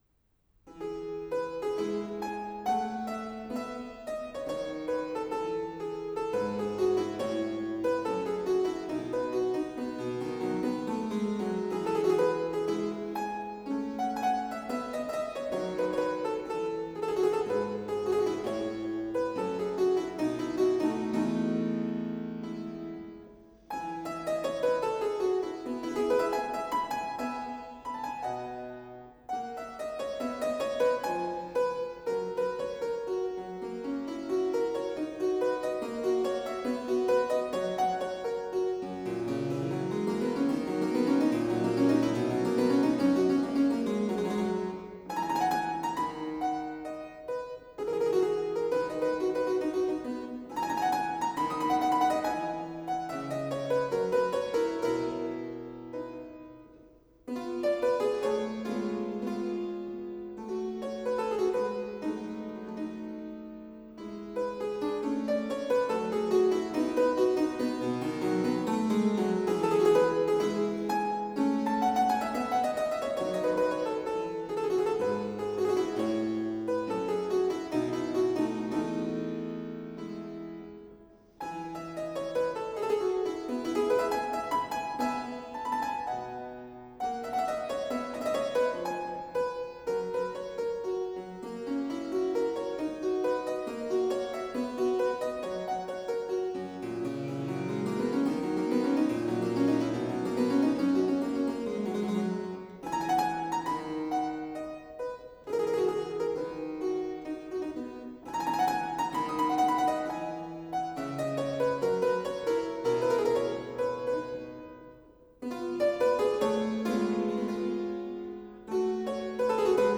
05-Sonatina-Dor-Pianoforte.flac